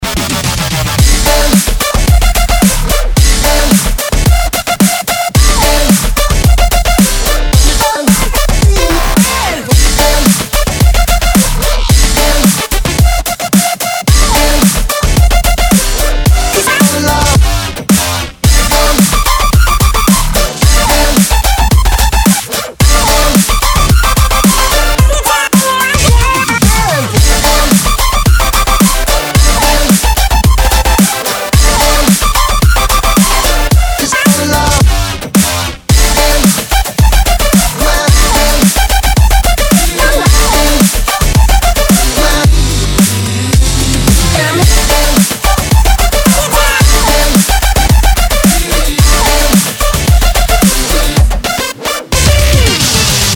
• Качество: 192, Stereo
Мощный новый Дабстеп рингтон